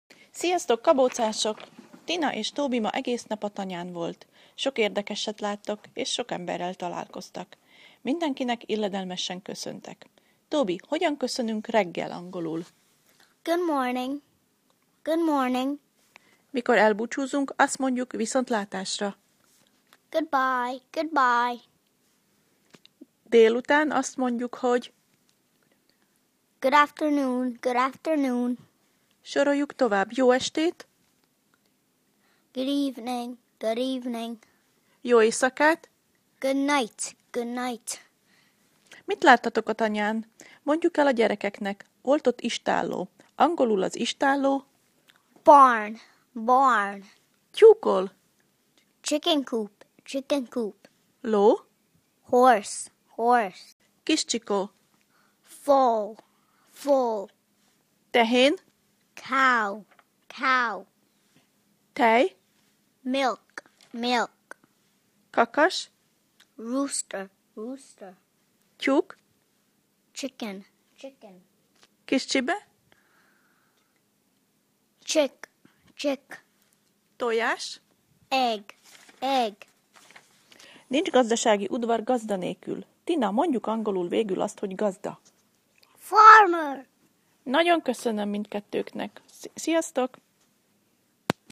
TobyAz ebben a leckében szereplő szavak helyes kiejtését hallgasd meg Tobytól